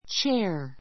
chair 小 A1 tʃéə r チェ ア 名詞 複 chairs tʃéə r z チェ ア ズ ❶ 椅子 いす ⦣ 1人用で背のあるもの.